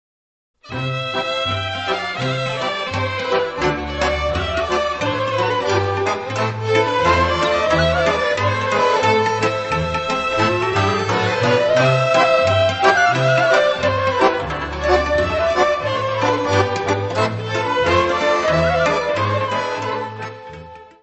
Área:  Tradições Nacionais